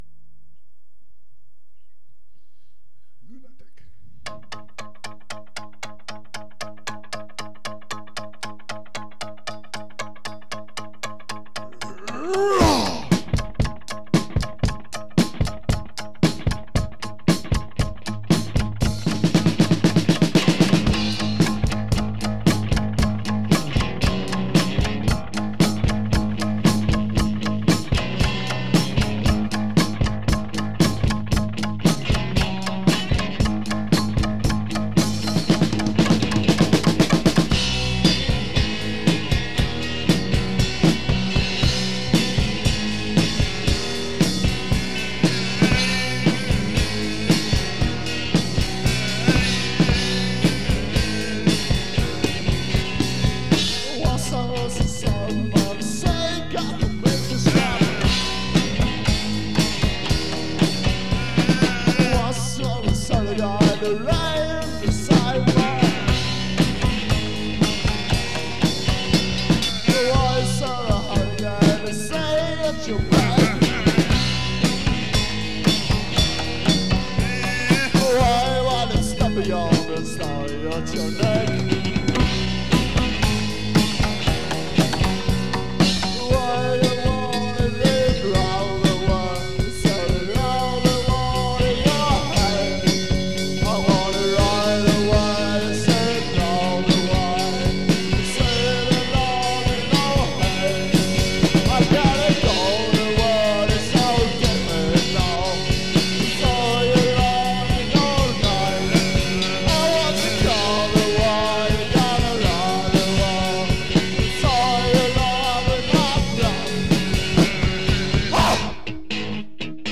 λάιβ κάπου στην Αργυρούπολη